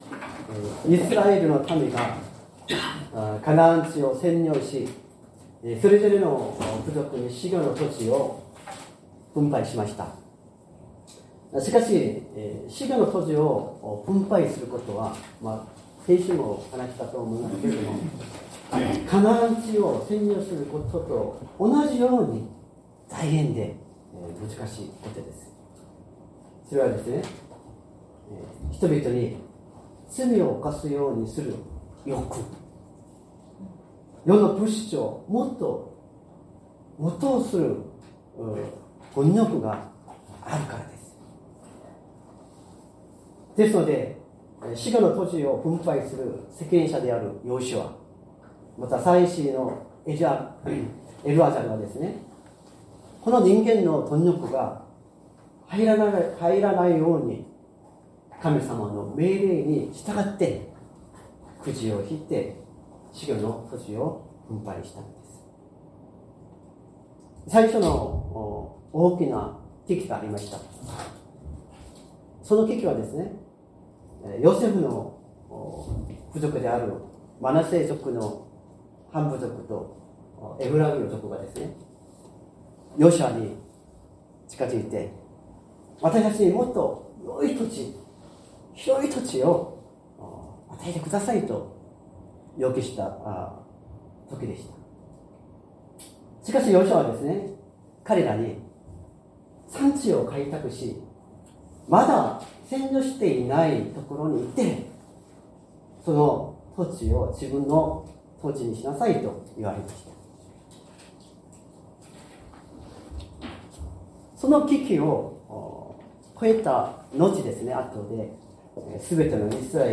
説教アーカイブ 2025年03月23日朝の礼拝「神様の人に必要な町」
礼拝説教を録音した音声ファイルを公開しています。